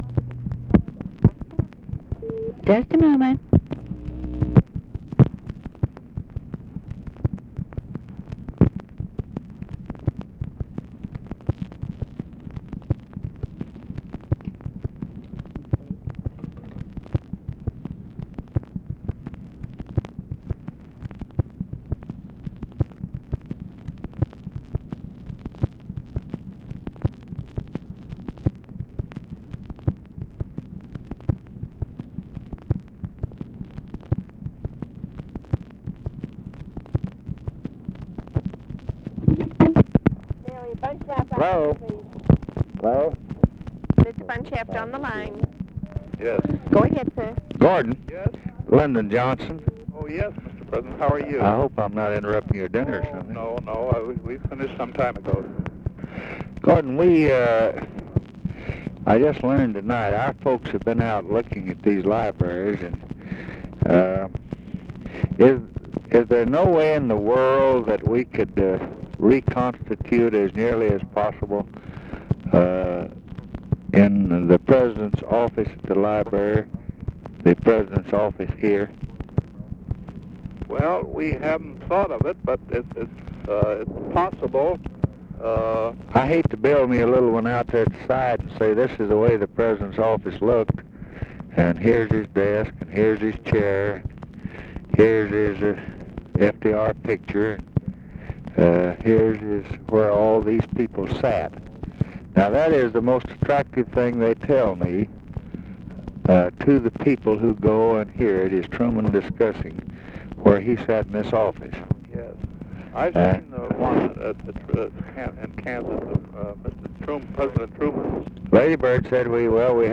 Conversation with GORDON BUNSHAFT, TELEPHONE OPERATOR and UNIDENTIFIED FEMALE, October 11, 1968
Secret White House Tapes